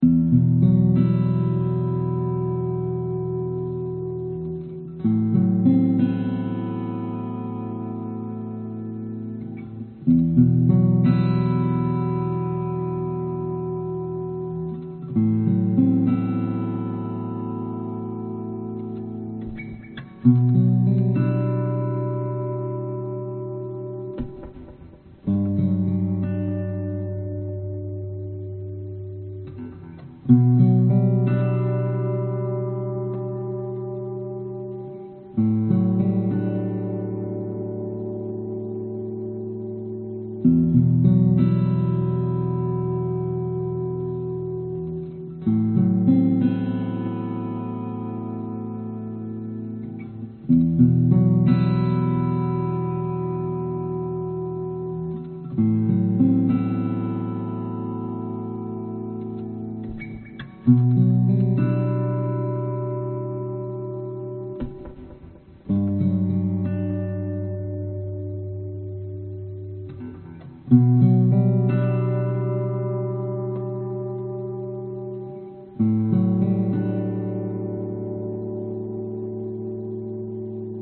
描述：在Washburn电声琴上录制。一条通道是音孔上的电容话筒，另一条通道是通过添加了fx的音量踏板DI到声卡中。
Tag: 原声 寒冷 吉他